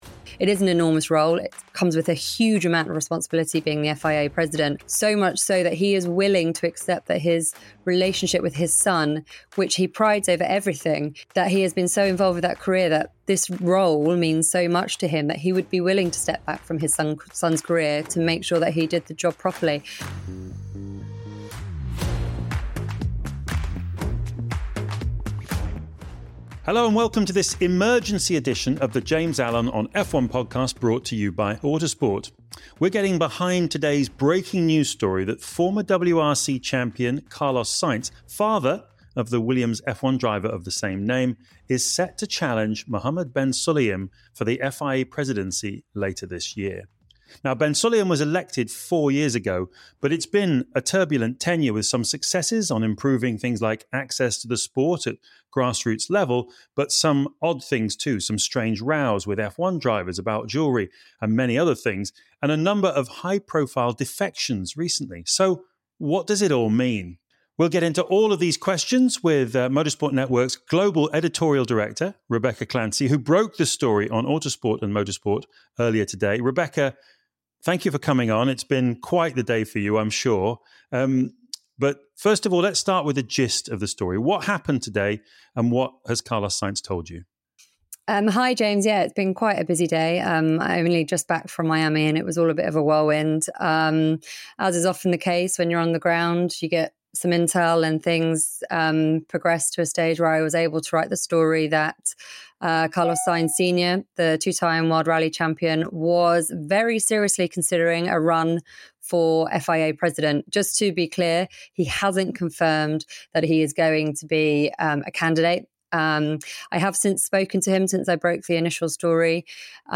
joins James Allen in the studio to discuss the bombshell news and to report what Sainz Sr has told her about his plans.